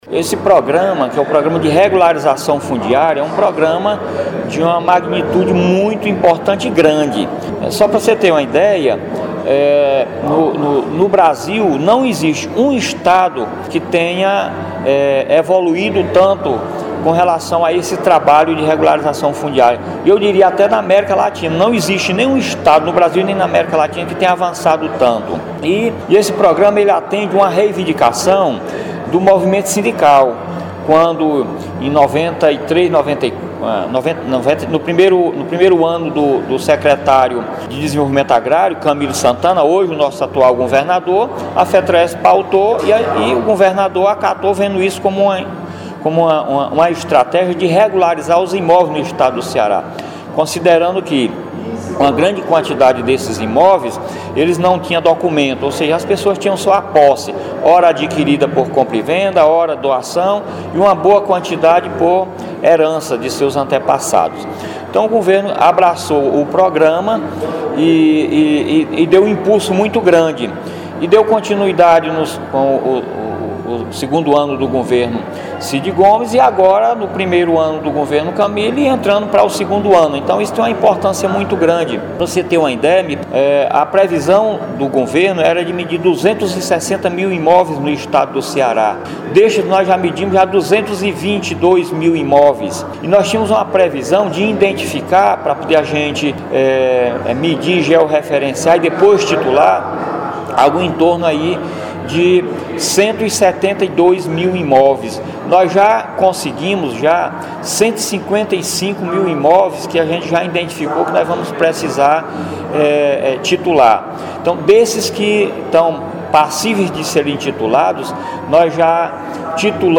Entrevista do Superintendente do Idace à Rádio São Francisco de Canindé.